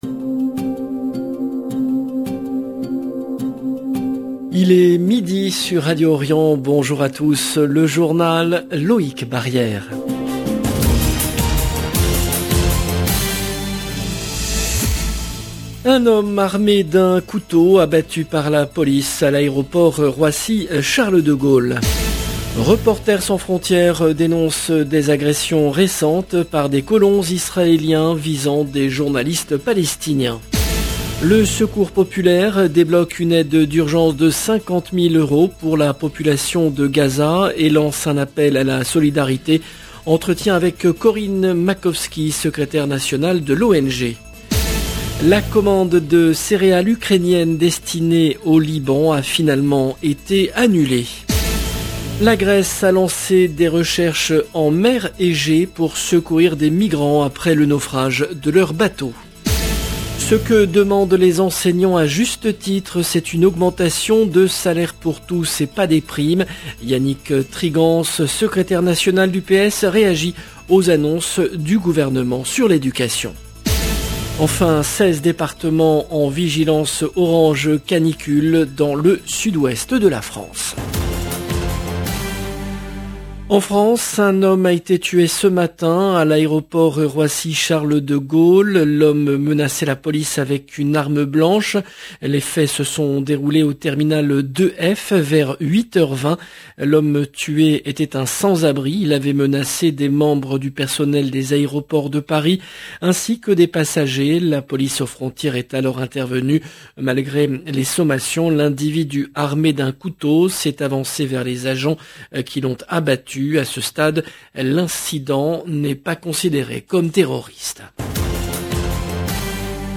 Journal
Entretien